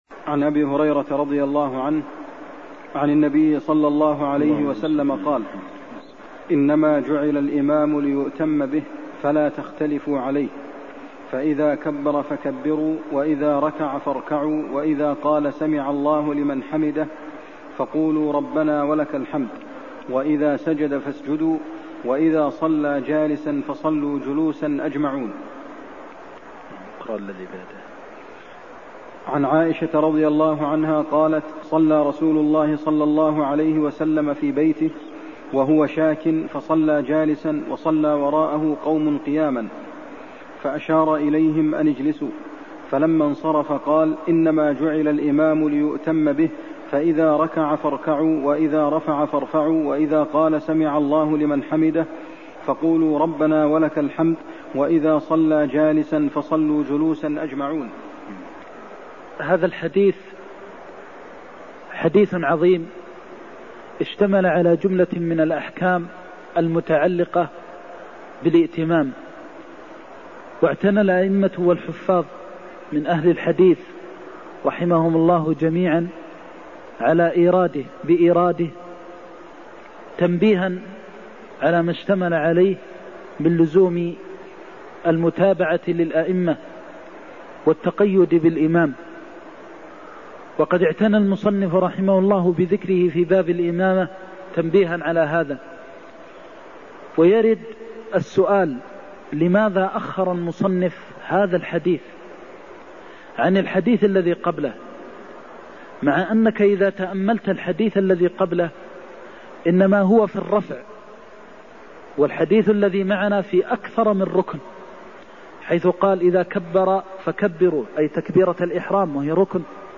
المكان: المسجد النبوي الشيخ: فضيلة الشيخ د. محمد بن محمد المختار فضيلة الشيخ د. محمد بن محمد المختار إنما جعل الإمام ليؤتم به فلا تختلفوا عليه (73)